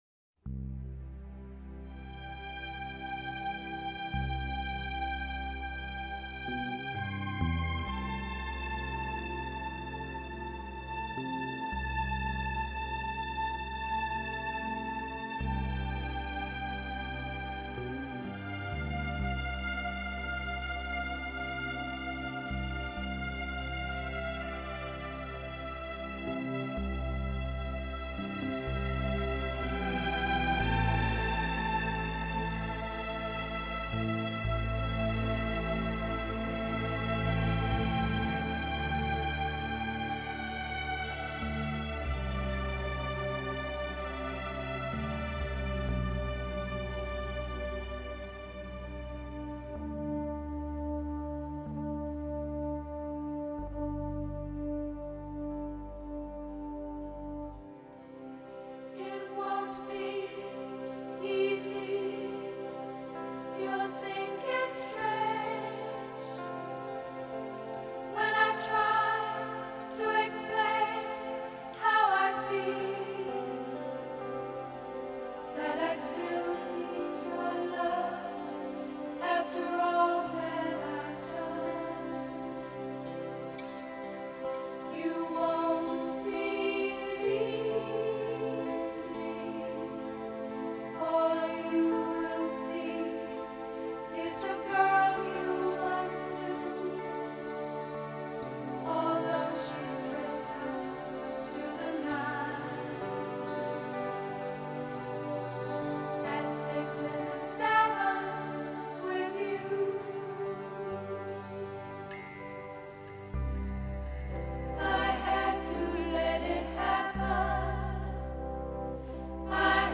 录制方式：ADD
或缠绵浪漫，或气势磅礴，或感怀动情，或兼而有之